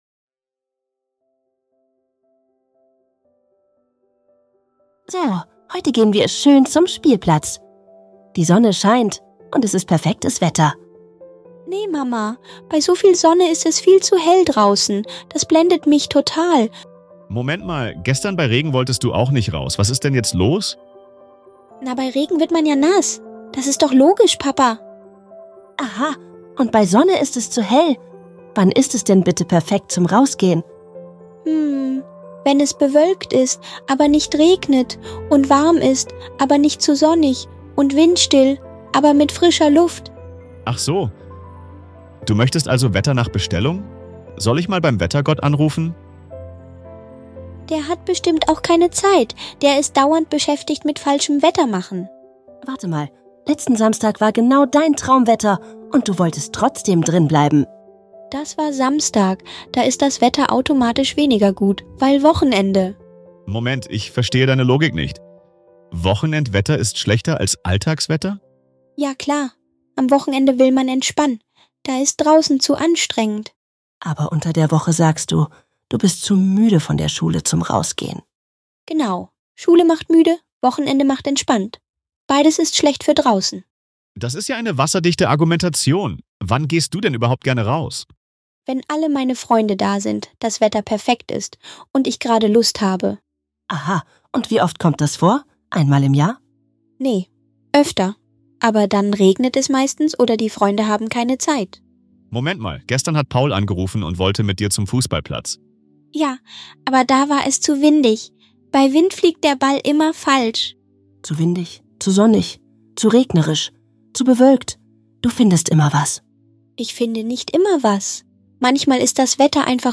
In diesem lustigen Gespräch entdeckt eine Familie die verrückte